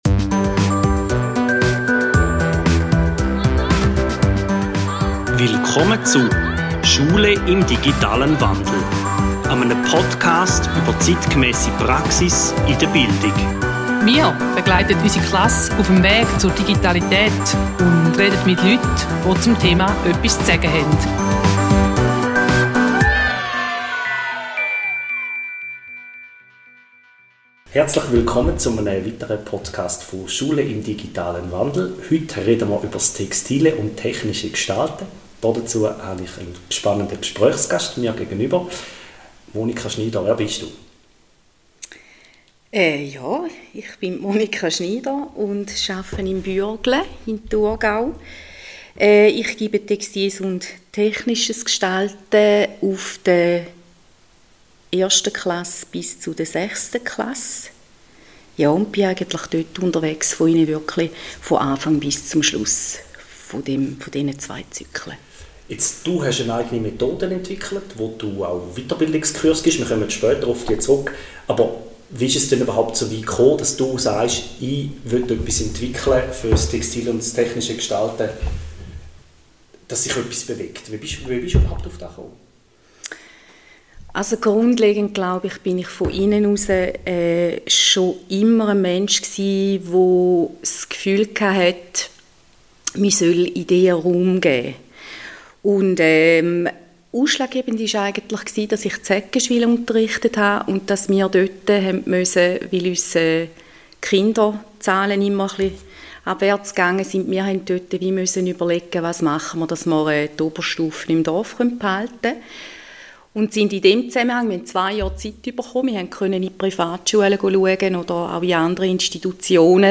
Intervew